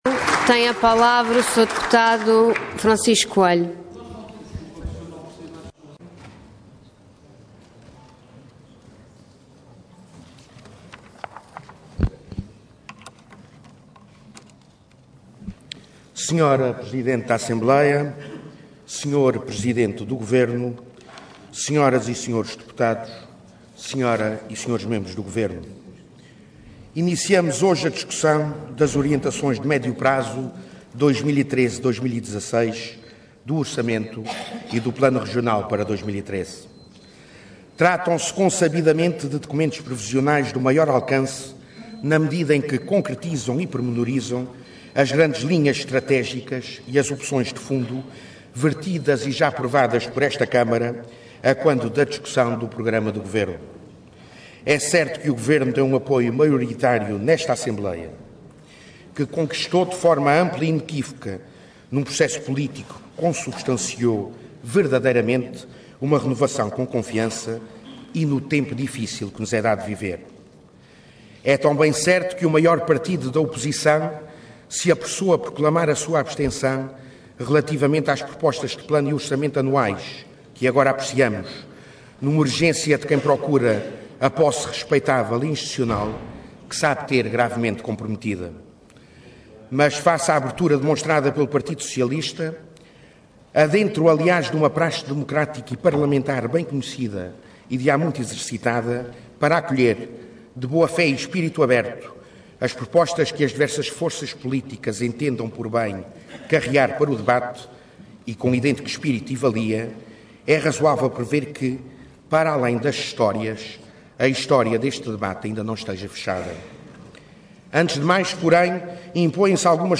Intervenção Intervenção de Tribuna Orador Francisco Coelho Cargo Deputado Entidade PS